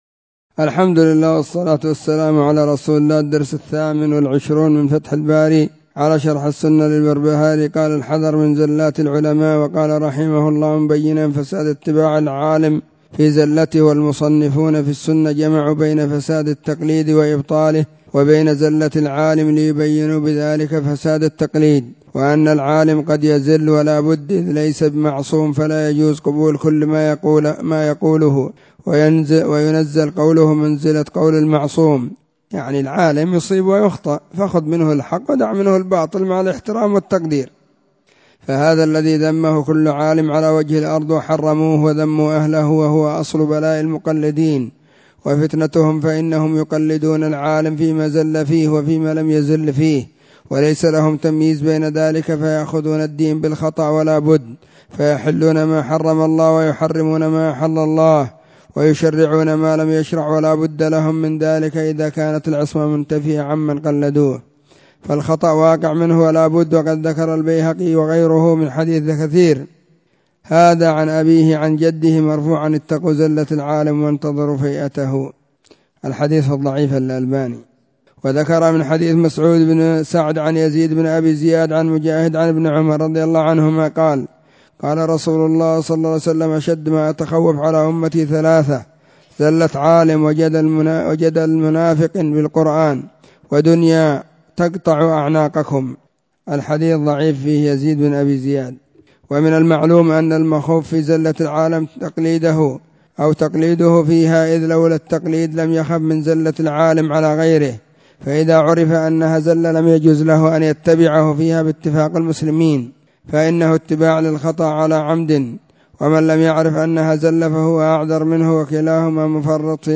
الدرس 28 من كتاب فتح الباري على شرح السنة للبربهاري
📢 مسجد الصحابة – بالغيضة – المهرة، اليمن حرسها الله.